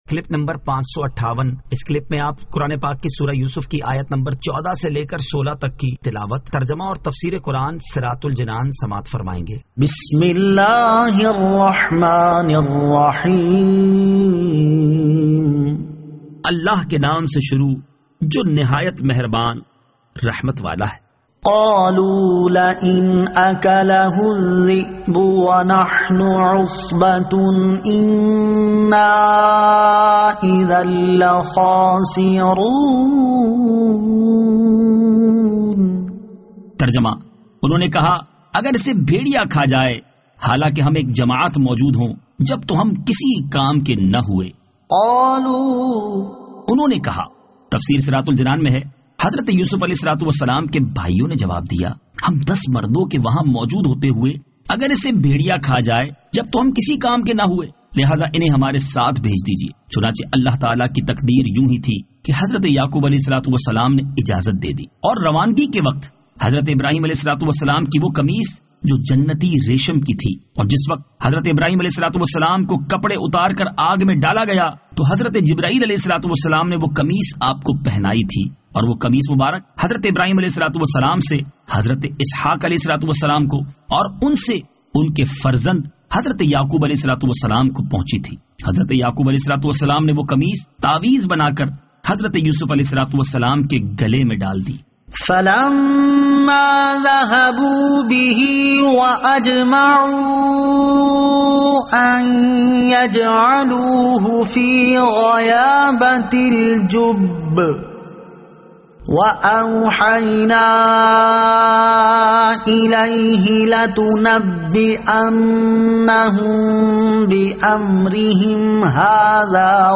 Surah Yusuf Ayat 14 To 16 Tilawat , Tarjama , Tafseer